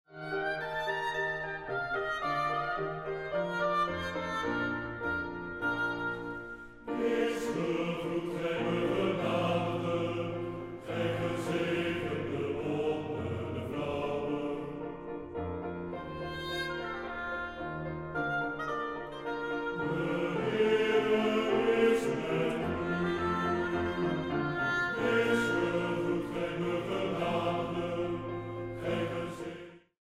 Orgel
Koor